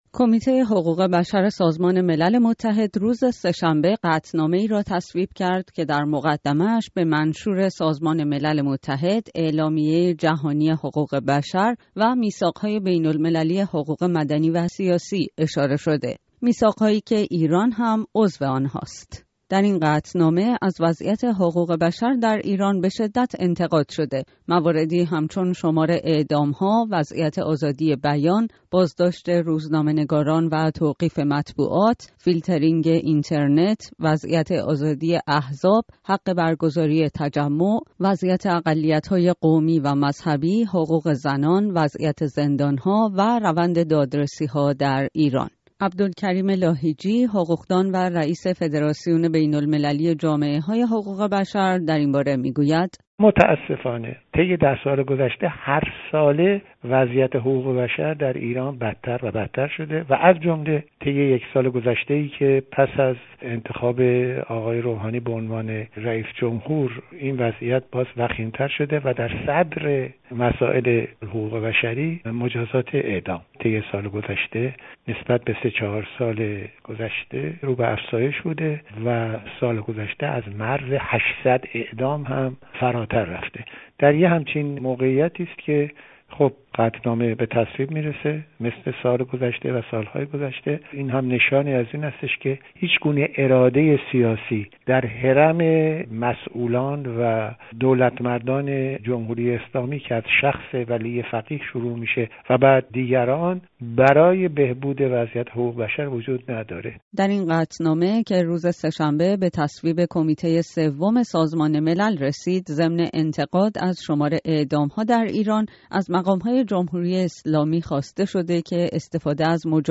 گفت‌وگوی